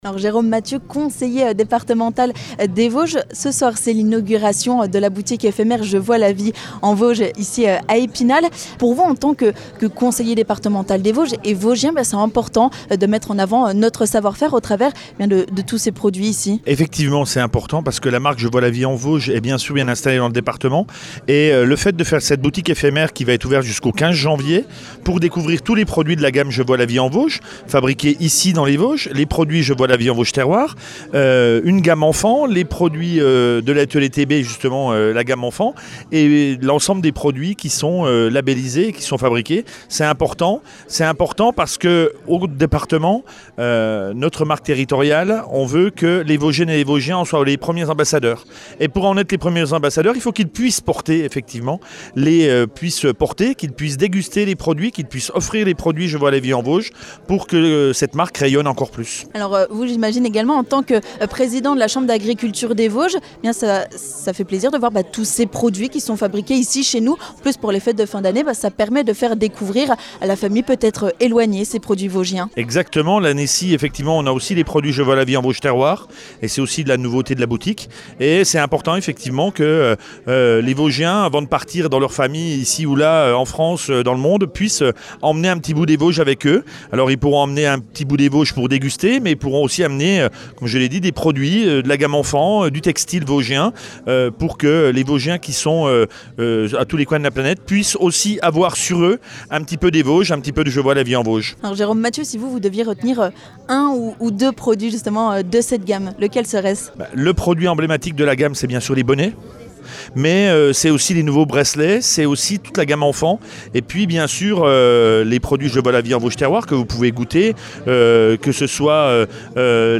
On en parle avec le vice-président du Conseil départemental des Vosges, Jérôme Mathieu.